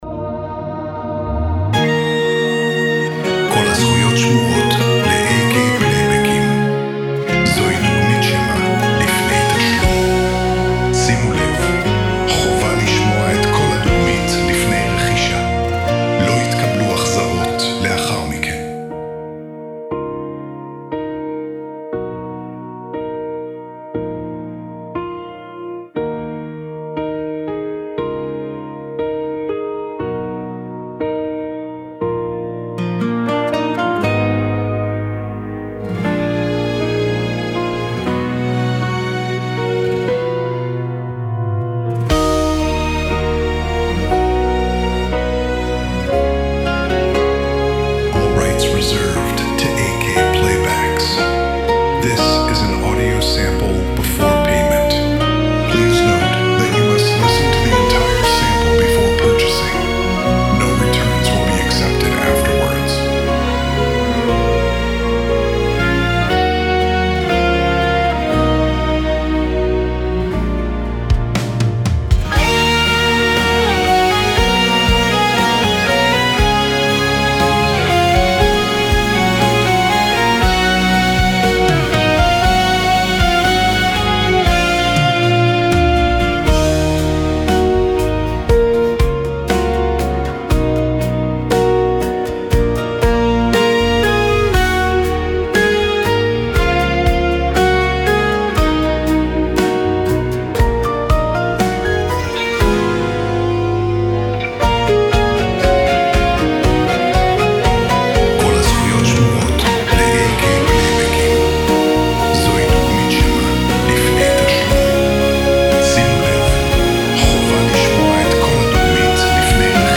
פלייבק חסידי
נאמן למקור